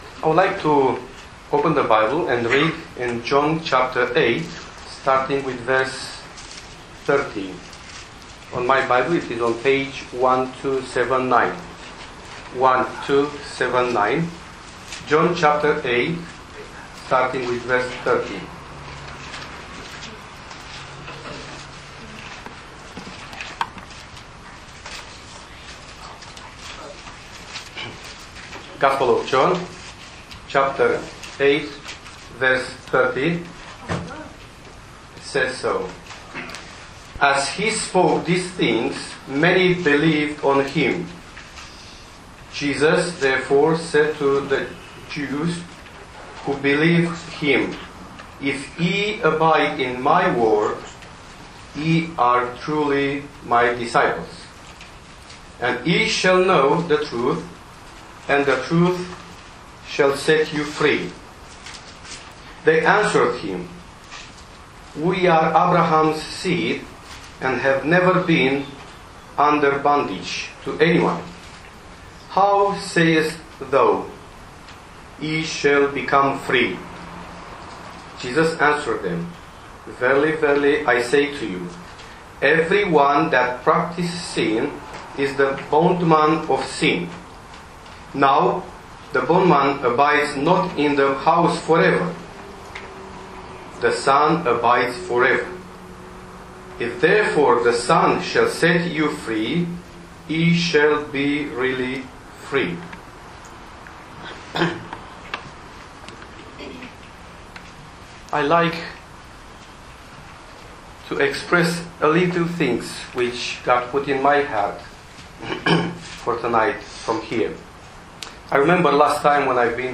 As you listen to this Gospel preaching you will discover that Freedom can be found by coming to Christ and accepting him as Saviour.